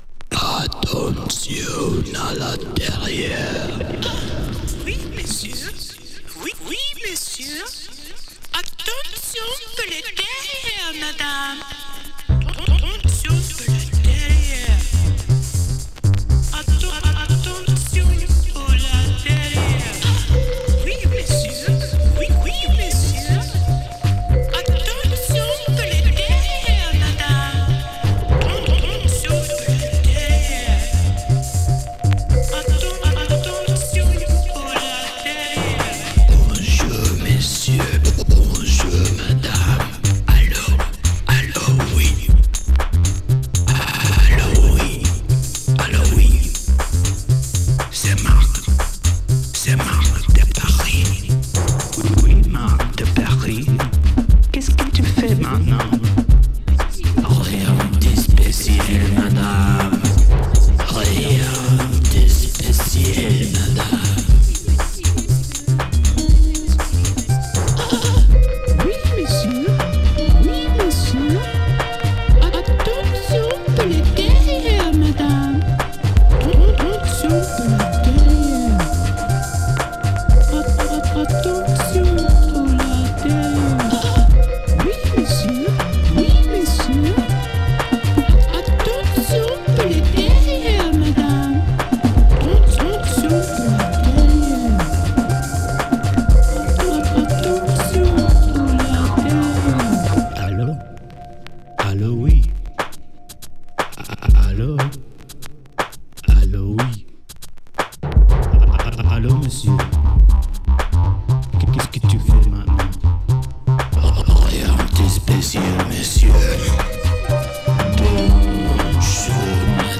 2. > TECHNO/HOUSE